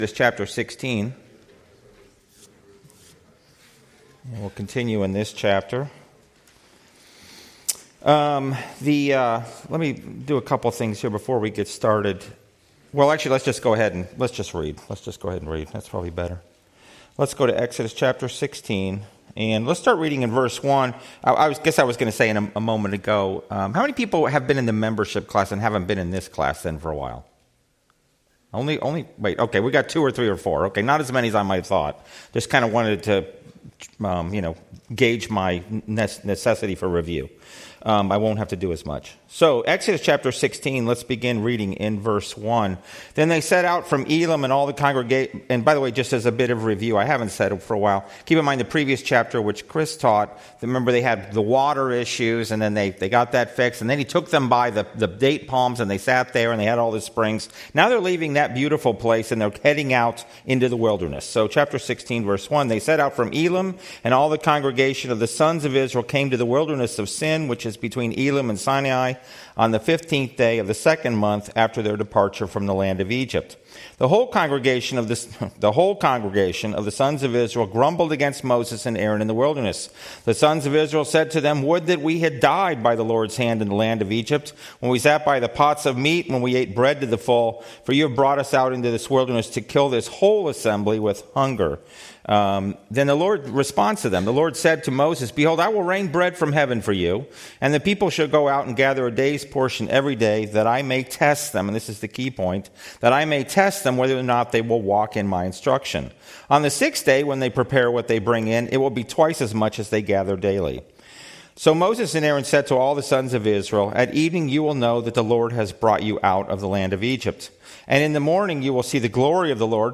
Passage: Exodus 16 Service Type: Sunday School